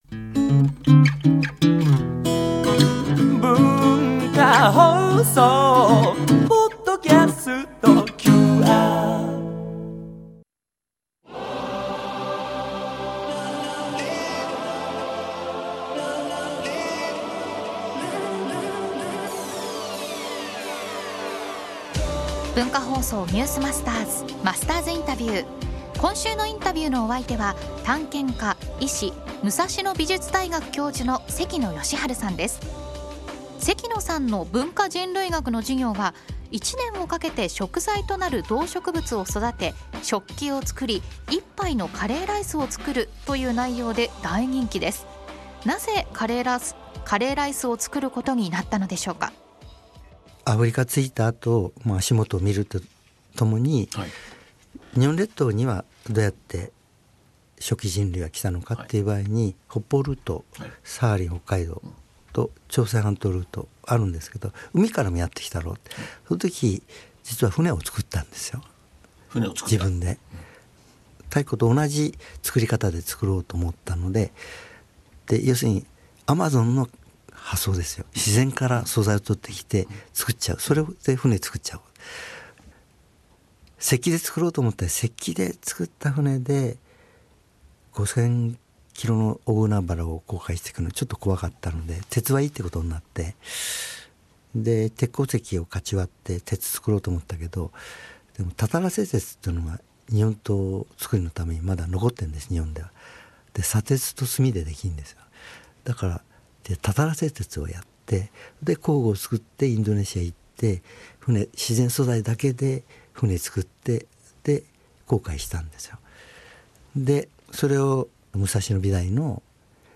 今週のインタビューのお相手は探検家・医師・武蔵野美術大学教授の関野吉晴さん。
（月）～（金）AM7：00～9：00　文化放送にて生放送！